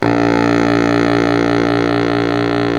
Index of /90_sSampleCDs/Roland L-CDX-03 Disk 1/SAX_Sax Ensemble/SAX_Solo Sax Ens
SAX BASS S12.wav